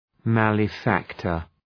Προφορά
{‘mælə,fæktər}